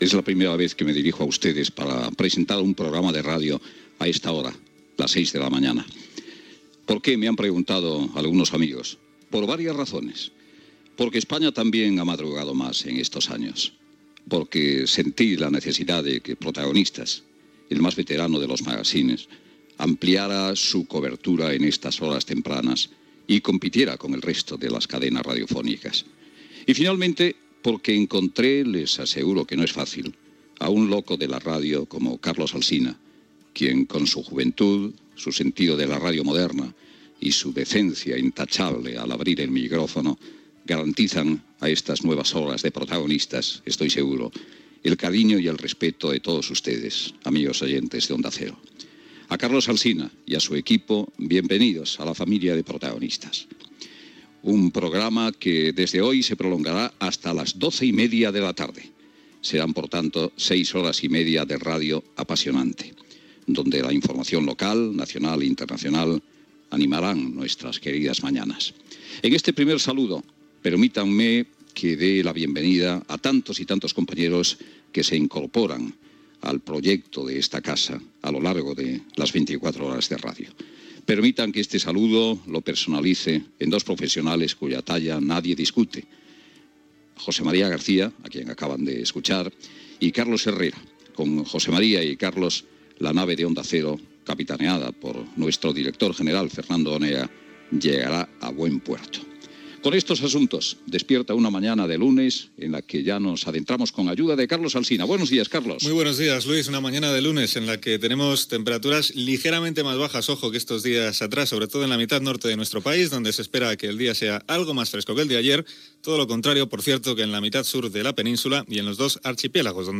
Salutació de Luis del Olmo en el primer dia que el programa començava a les 06.00 del matí
Info-entreteniment